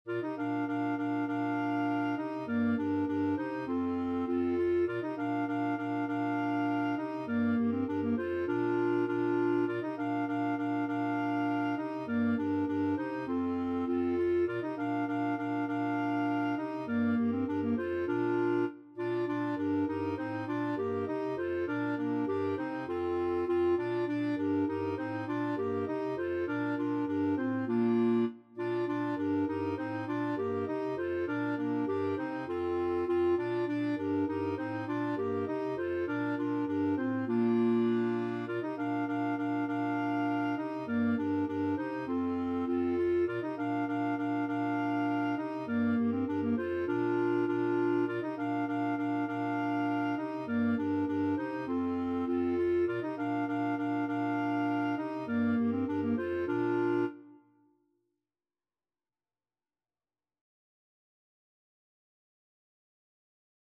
Free Sheet music for Clarinet Quartet
Clarinet 1Clarinet 2Clarinet 3Clarinet 4/Bass Clarinet
Bb major (Sounding Pitch) C major (Clarinet in Bb) (View more Bb major Music for Clarinet Quartet )
4/4 (View more 4/4 Music)
Classical (View more Classical Clarinet Quartet Music)
danserye_5_morisque_4CL.mp3